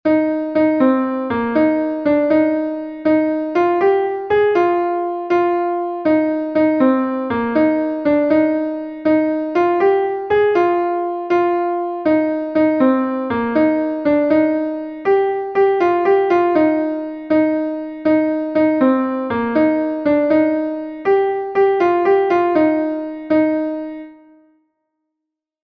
Gavotenn Poulaouen is a Gavotte from Brittany